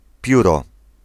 Ääntäminen
Vaihtoehtoiset kirjoitusmuodot (vanhahtava) fether Synonyymit feathering feathers horsefeathers Ääntäminen US RP : IPA : [ˈfɛðə] IPA : /ˈfɛð.ə(ɹ)/ GenAm: IPA : [ˈfɛðɚ] Tuntematon aksentti: IPA : [ˈfɛ.ðɝ]